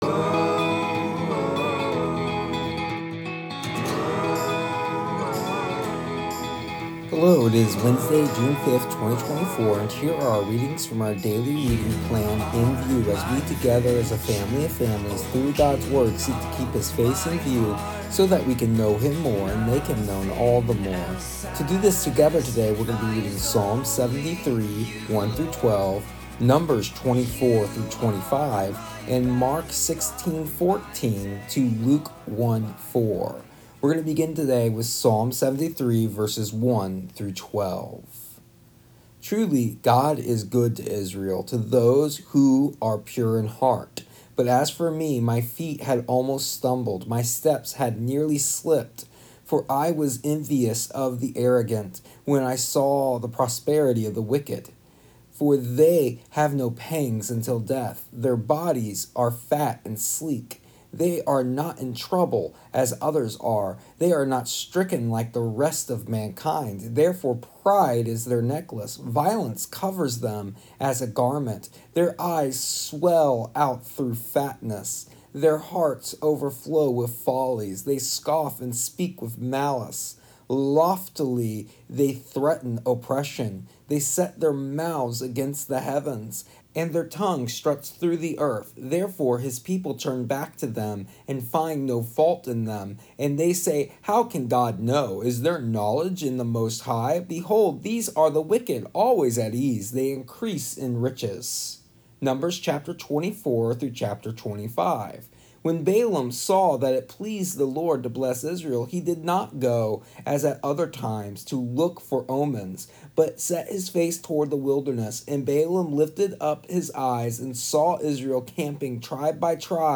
Here is the audio version of our daily readings from our daily reading plan “Keeping His Face in View” for June 5th, 2024.